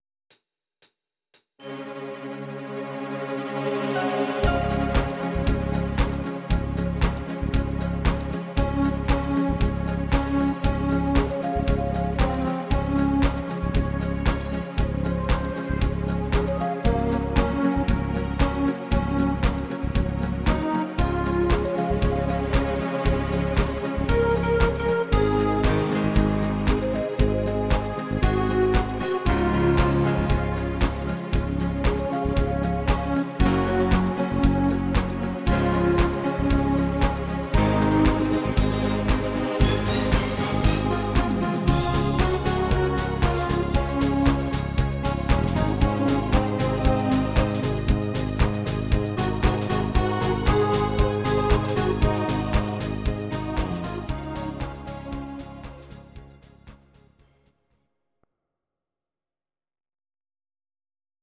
Demo/Kauf Midifile
Stil: Deutscher Schlager
- GM = General Midi Level 1
- Keine Vocal Harmony